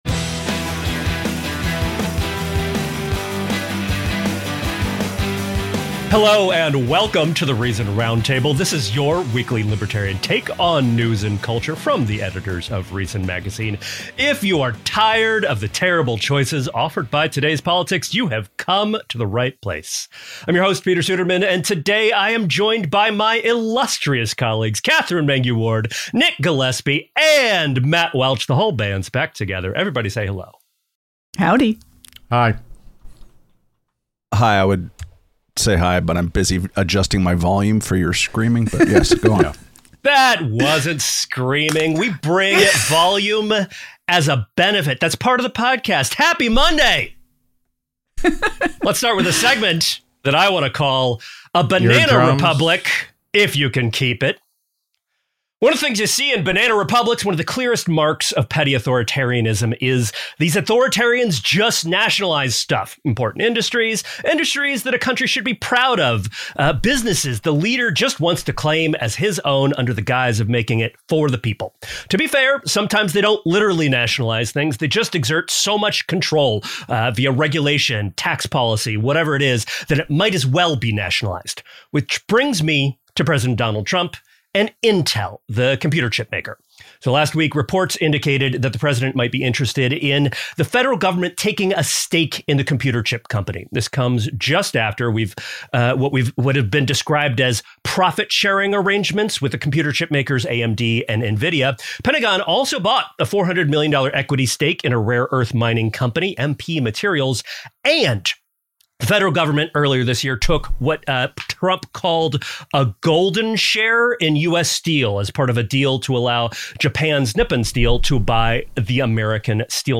On this week's episode of the Reason Roundtable, we dig into the Trump administration's increased meddling in the tech sector.